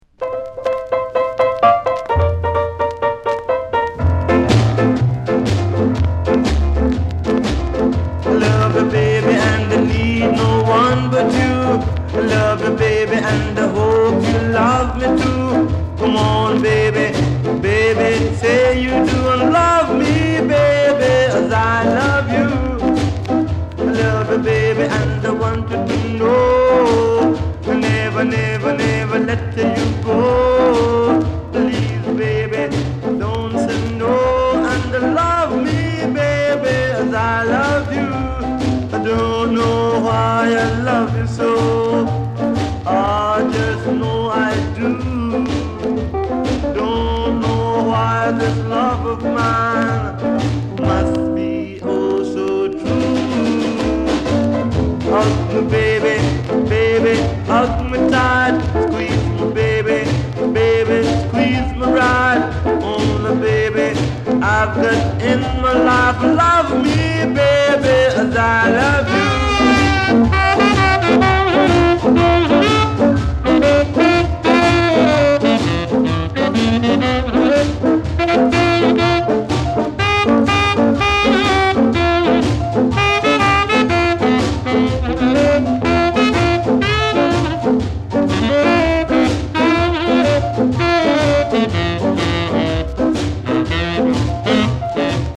スカ・レゲエ
60年代中期のヴィンテージ感がたまらんです。